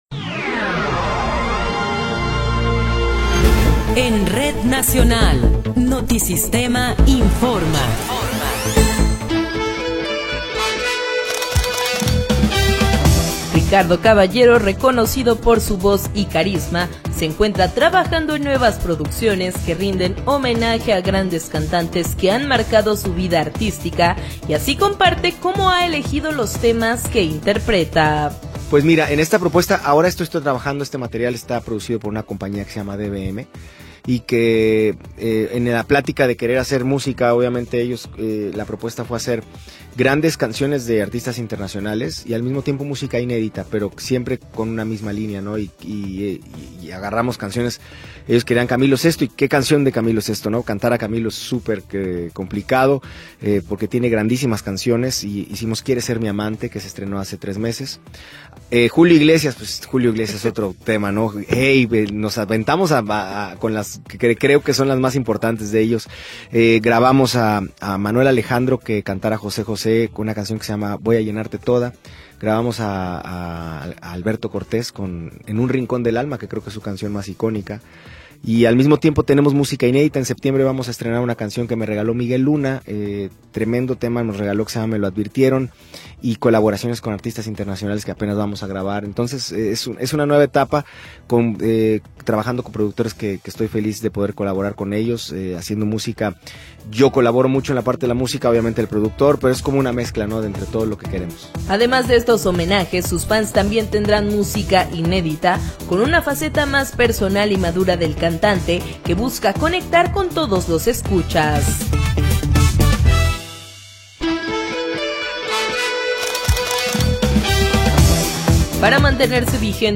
Noticiero 19 hrs. – 1 de Enero de 2026
Resumen informativo Notisistema, la mejor y más completa información cada hora en la hora.